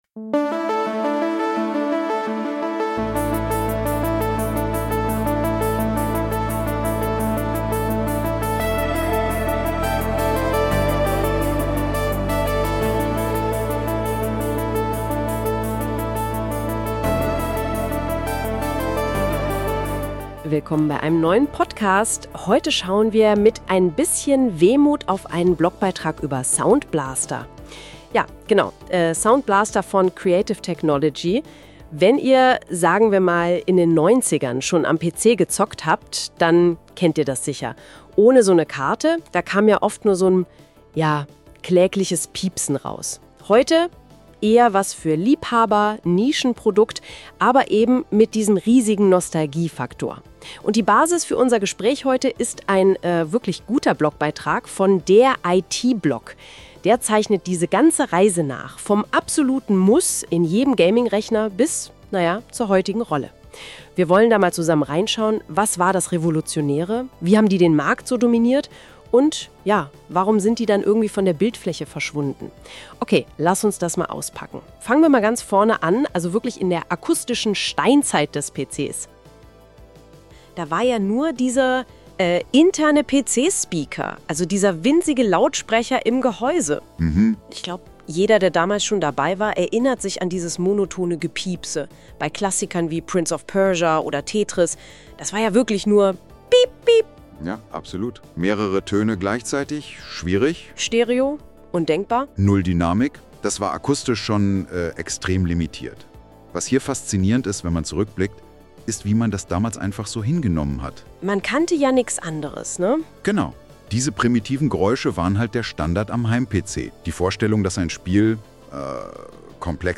Dieser Podcast wurde mit Unterstützung von Gemini Deep Research, NotebookLM und Ideogram (Bild) erstellt.